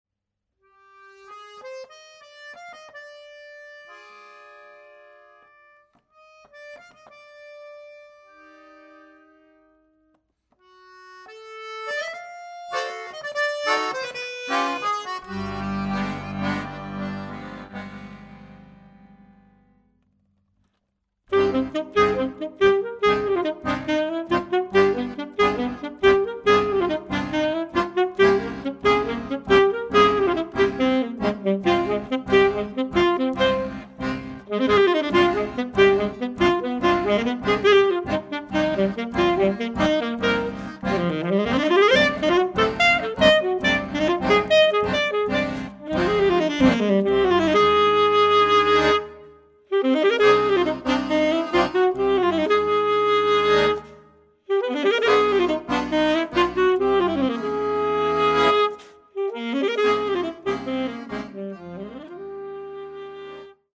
chant (soprano)
saxophone(s)
piano, chant, arrangement
alto, accordéon,arrangement